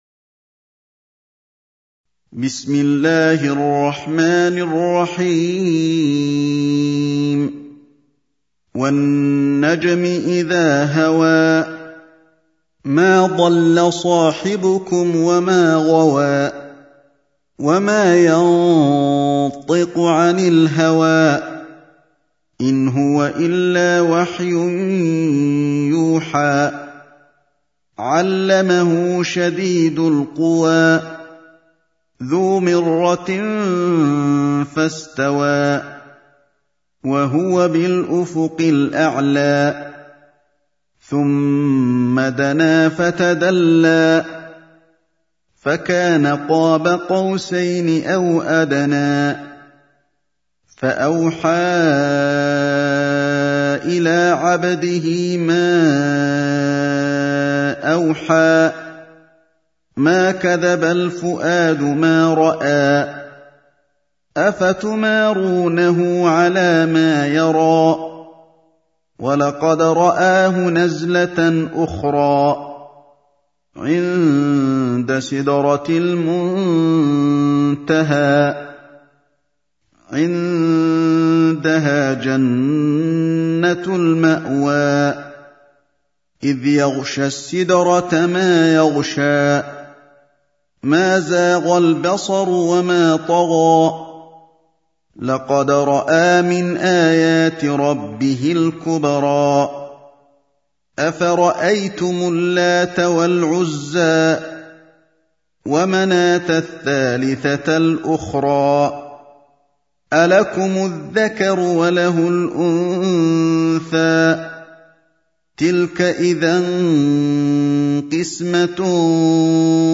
Audio Quran Tarteel Recitation
Surah Repeating تكرار السورة Download Surah حمّل السورة Reciting Murattalah Audio for 53. Surah An-Najm سورة النجم N.B *Surah Includes Al-Basmalah Reciters Sequents تتابع التلاوات Reciters Repeats تكرار التلاوات